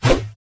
swing1.wav